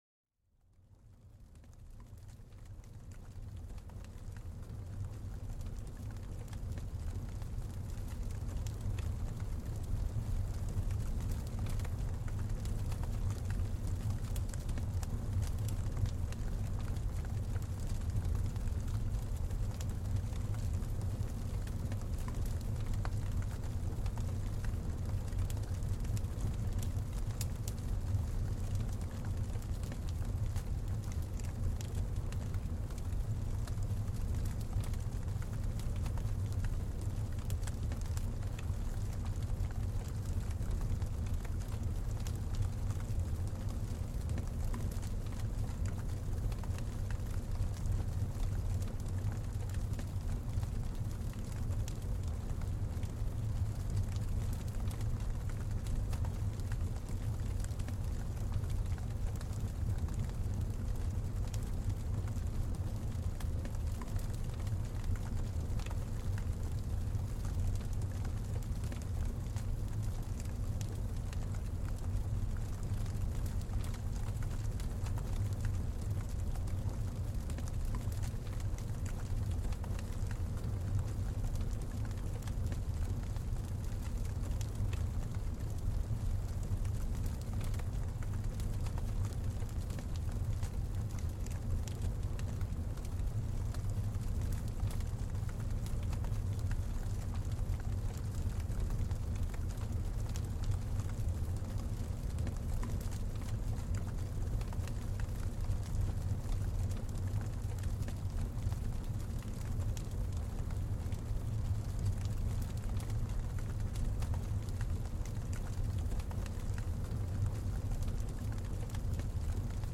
Plongez dans l'atmosphère apaisante des crépitements d'un feu de camp. Laissez les flammes vous emporter dans un état de relaxation profonde.